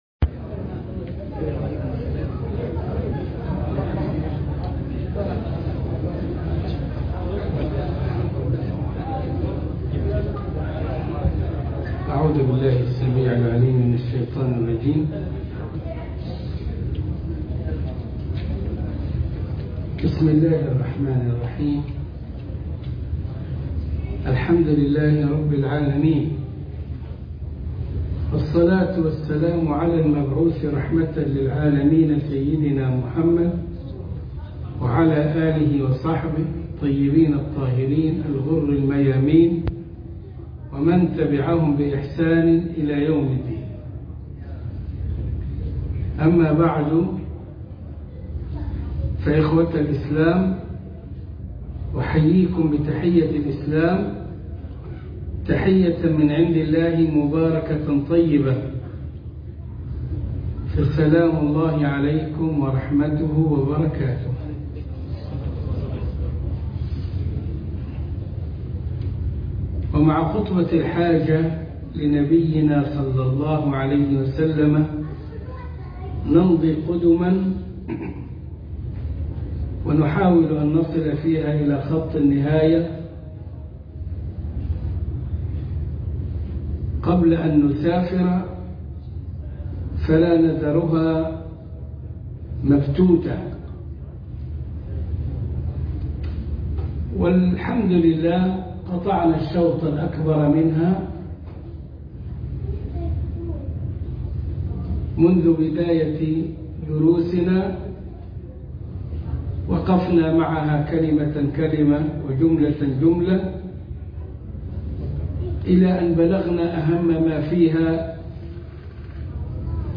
خاطرة المغرب من مسجد الروضة من مونتريالكندا 4